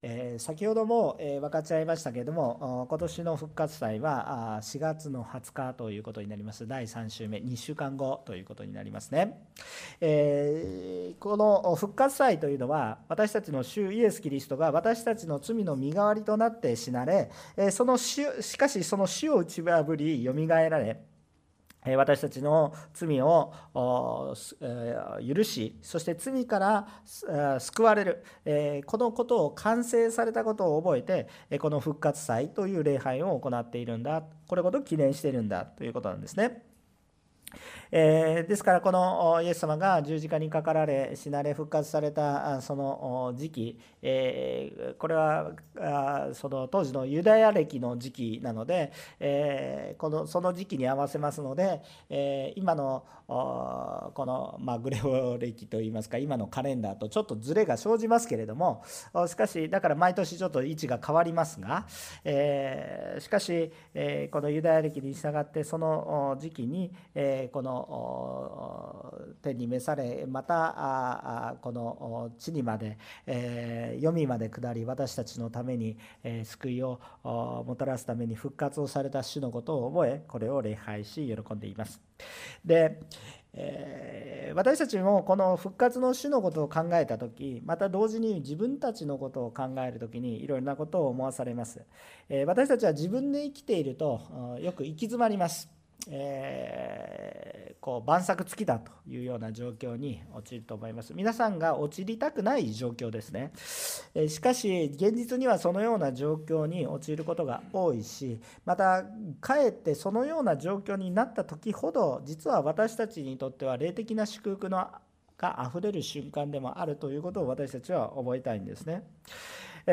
横浜オンヌリキリスト教会の説教を配信します。